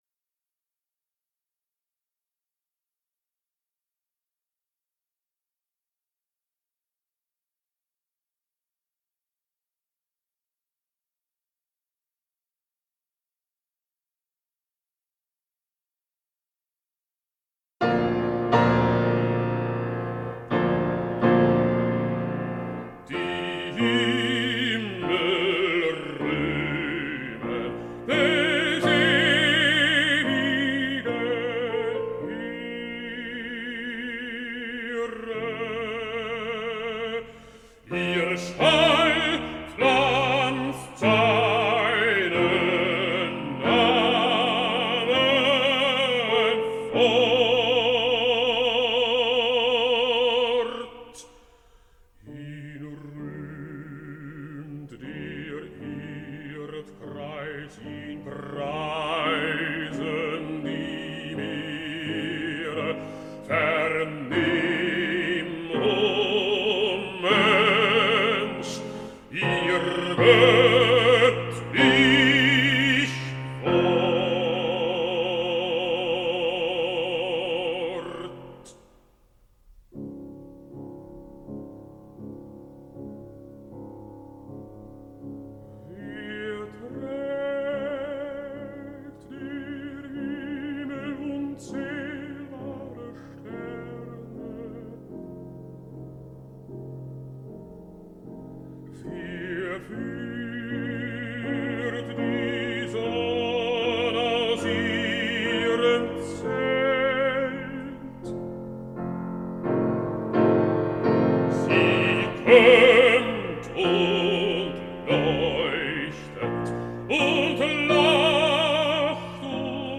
Vox Populi Choir is a community choir based in Carlton and open to all comers.
Die_Ehre_Gottes_Aus_Der_Natur_Baritone.mp3